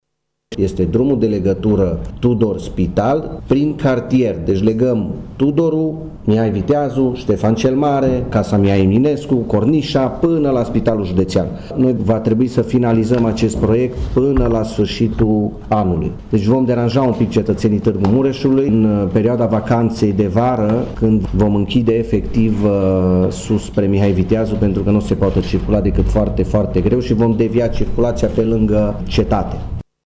Viceprimarul Claudiu Maior a precizat că lucrările încep în luna mai și că nu e vorba de un drum care trece prin pădurea Cornești: